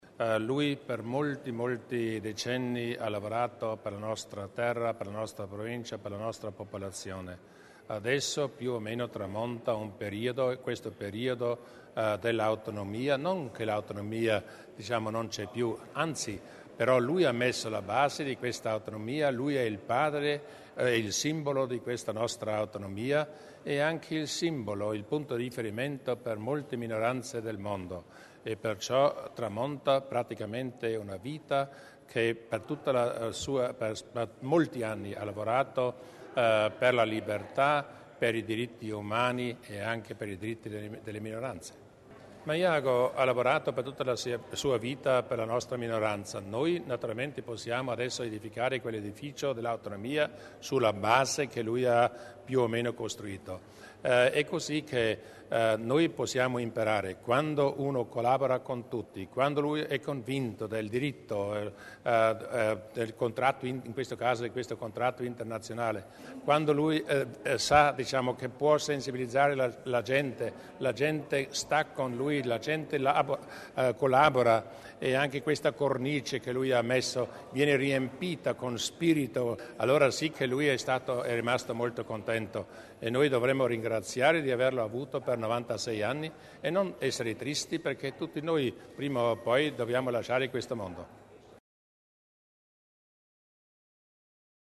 Il Presidente Durnwalder ricorda Silvius Magnago
In segno di omaggio nei confronti di Magnano i componenti dell’Esecutivo hanno quindi preso parte alla conferenza stampa delle ore 12,30 che è stata dedicata interamente al ricordo ed alla celebrazione di Silvius Magnano da parte del presidente Luis Durnwalder.